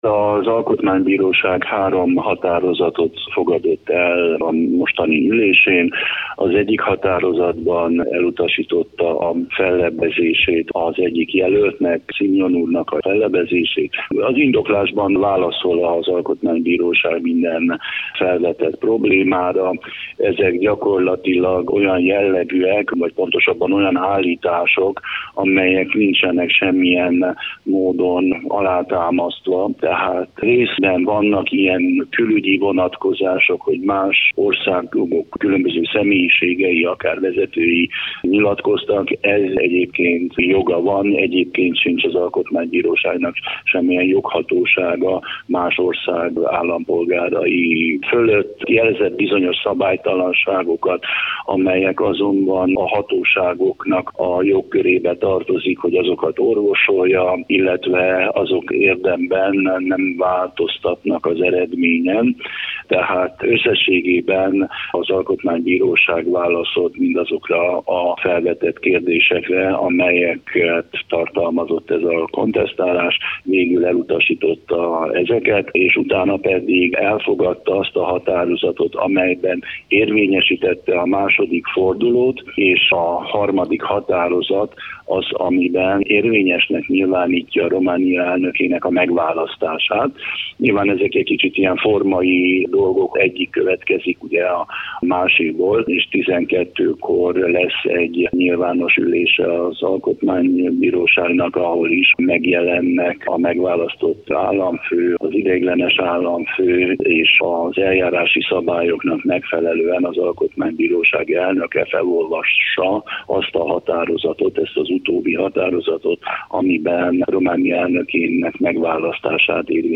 A taláros testület egyhangú döntést hozott az óvás elutasításáról. A döntés indoklásáról Varga Attila alkotmánybíró nyilatkozott a Kolozsvári Rádiónak.